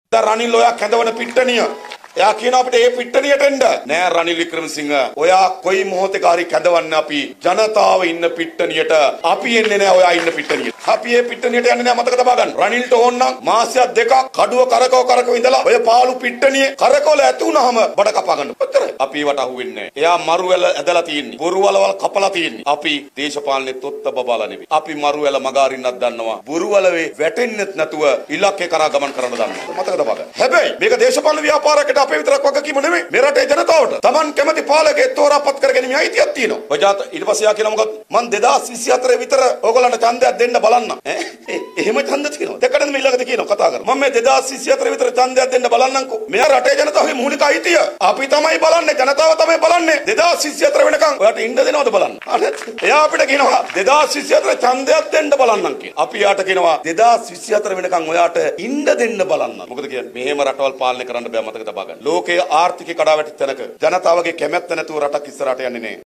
මේ අතර ඊයේ පැවති ජනහමුවකදී ජාතික ජනබලවේගයේ නායක අනුර කුමාර දිසානායක මහතා ද මැතිවරණය කල් දැමීම සම්බන්ධයෙන් අදහස් පළ කළා.